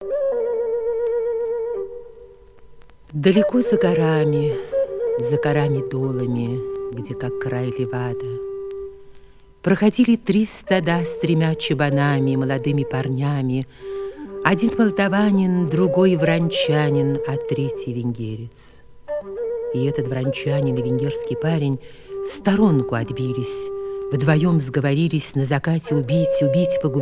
Catégorie Récit